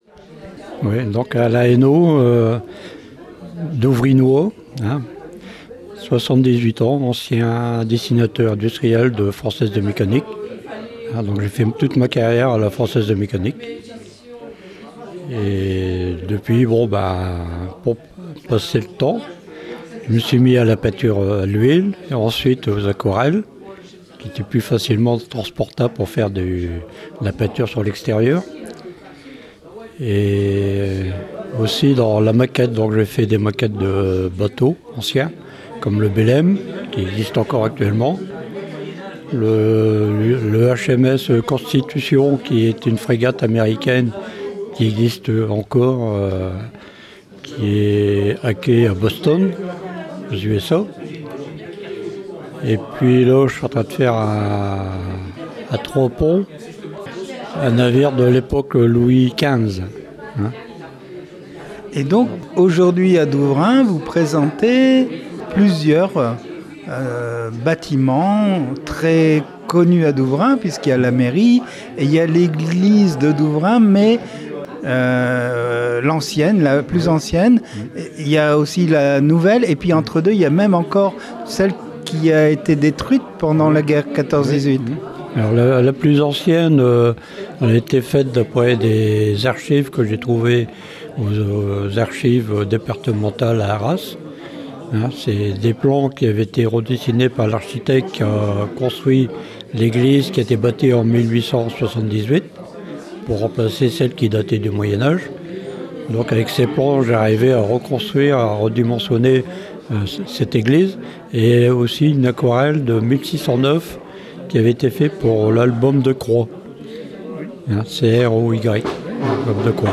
l'entretien